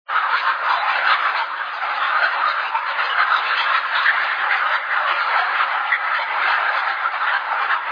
Scary Halloween Ghost Voices
Freaky voices
32kbps-triond-whisper.mp3